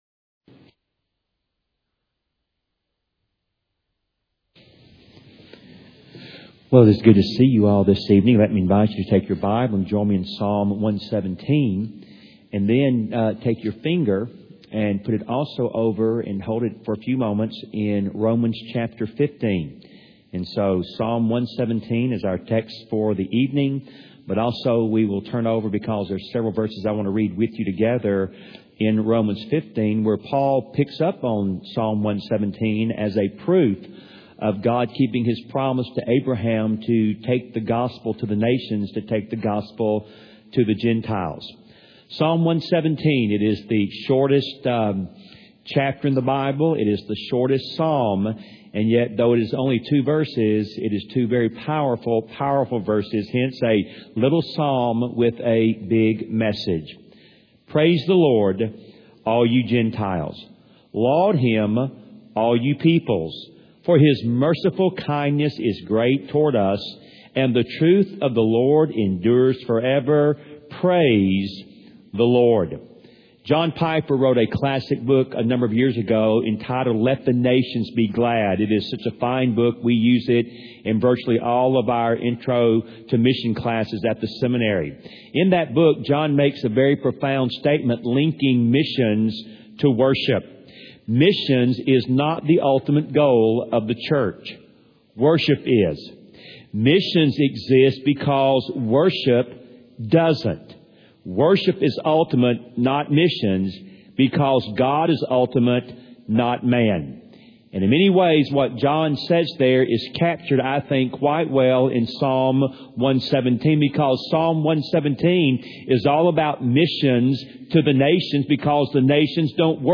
Part of a series entitled “Through the Psalms” delivered at Wake Cross Roads Baptist Church in Raleigh, NC